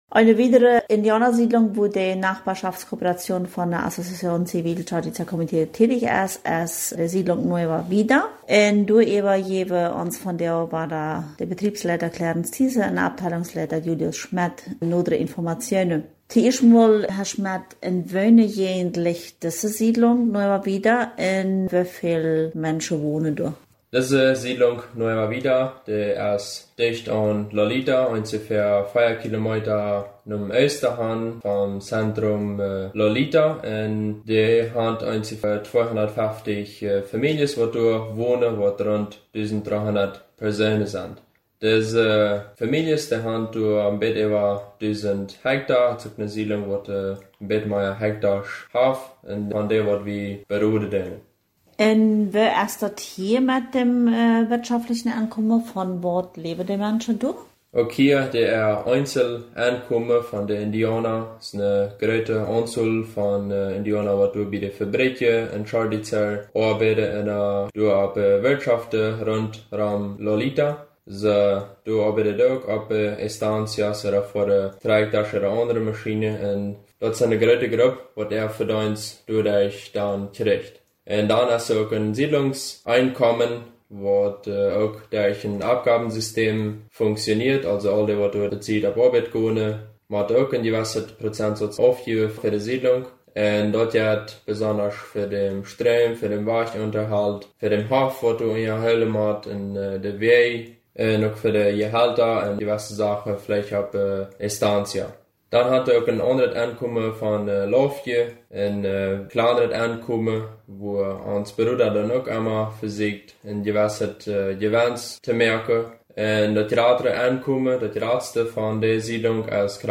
2024-05-07_Interview